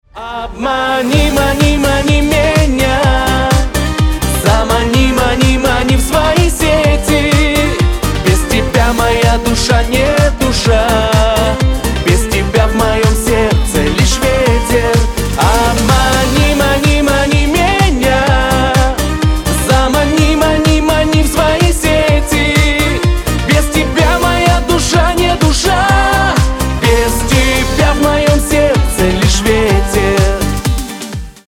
• Качество: 320, Stereo
мужской голос